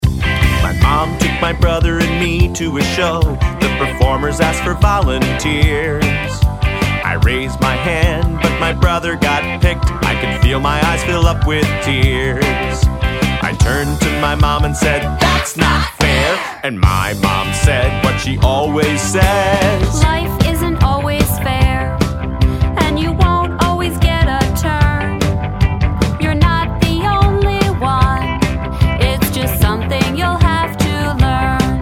Listen to a sample of this song